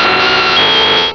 Cri de Nidoking dans Pokémon Rubis et Saphir.